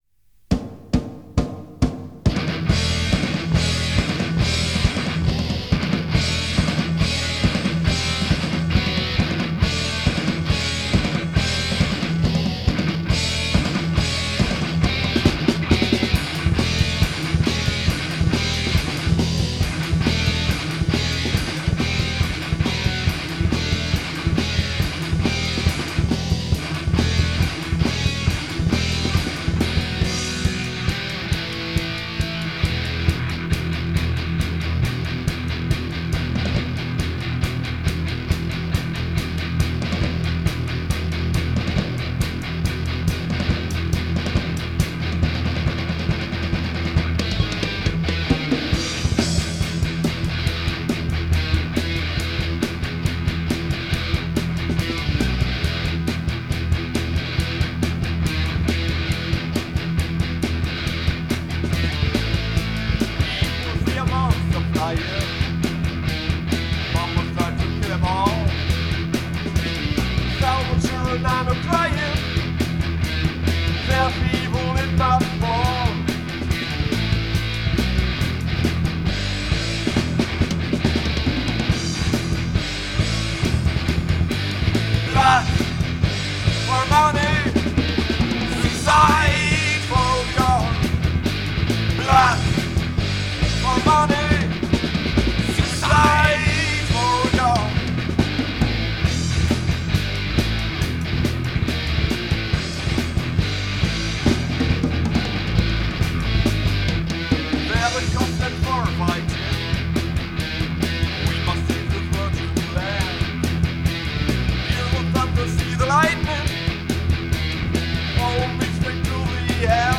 [Demo Tape 1993]